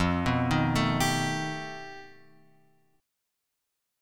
F 9th Flat 5th